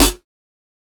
Snare 011.wav